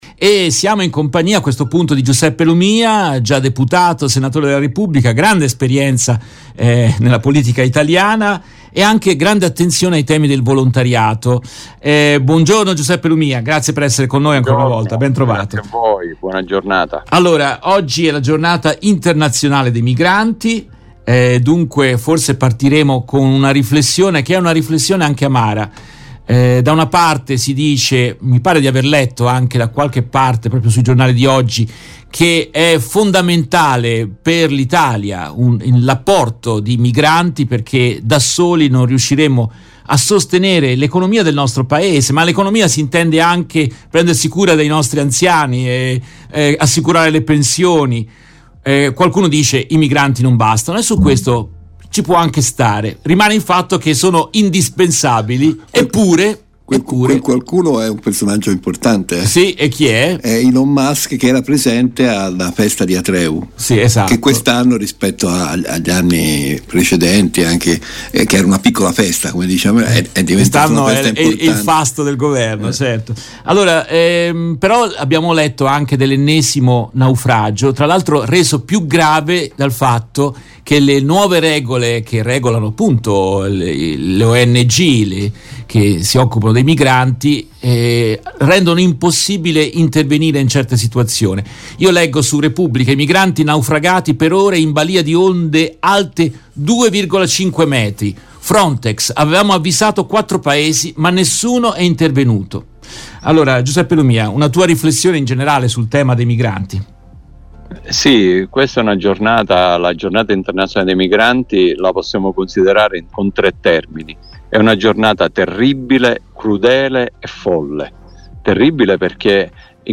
Nel corso della trasmissione in diretta del 18 dicembre 2023